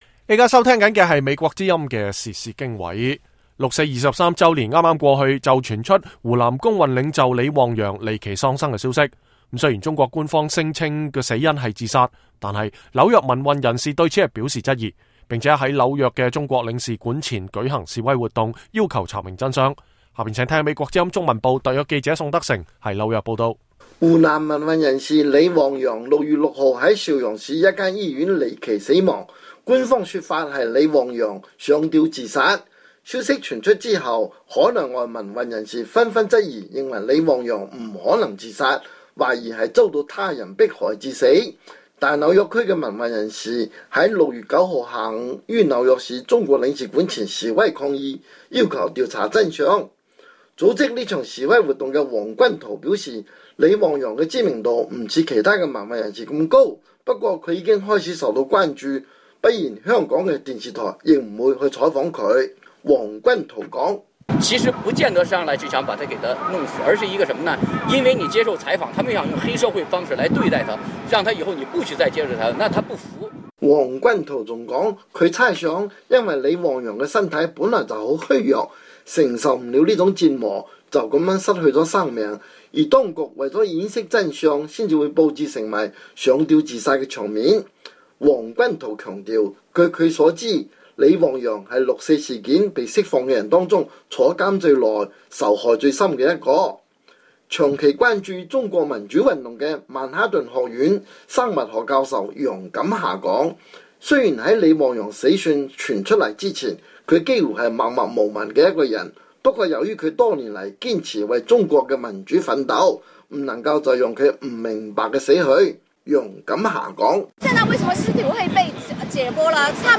紐約報導